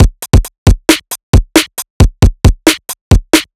Mover Break 135.wav